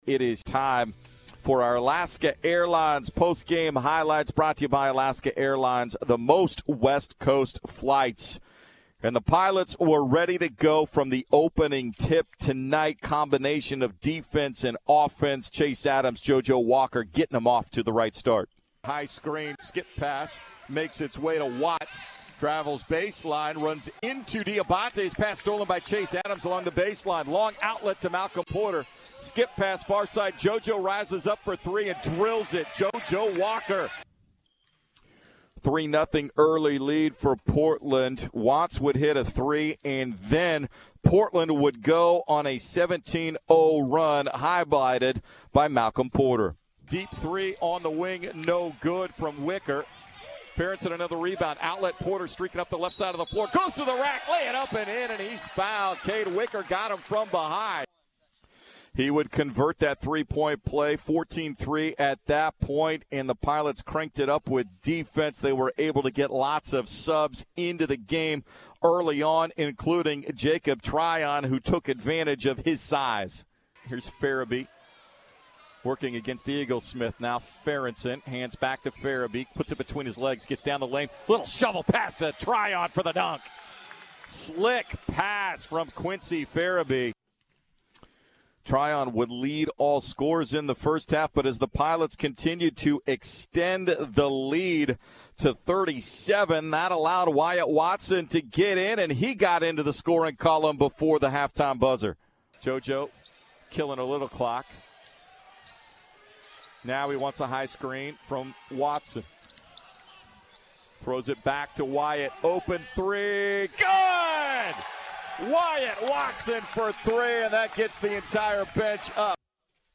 November 05, 2019 Post-game radio record of Portland's 86-36 season-opening win against Willamette University on Nov. 5, 2019.